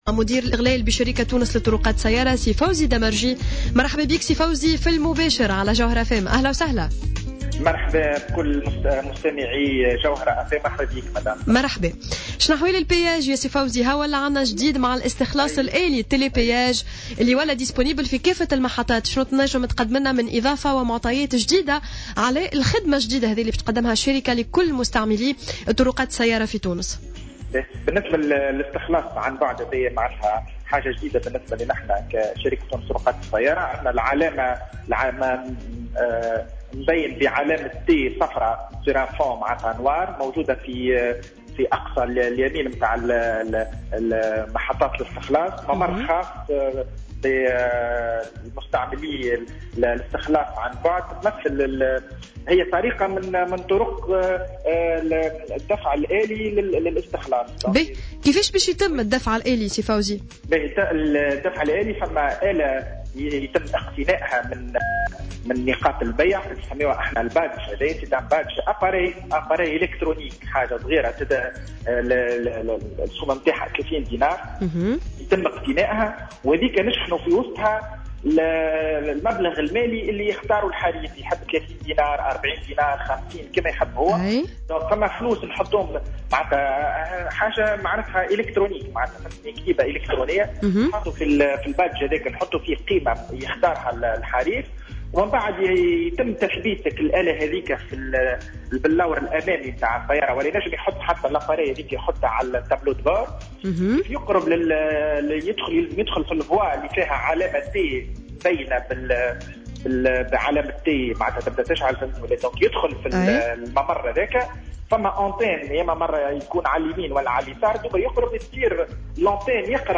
وأوضح في مداخلة هاتفية مع "الجوهرة أف أم" بـ "صباح الورد" أن هذه الآلية اختيارية وتساعد على تجنب الاكتظاظ.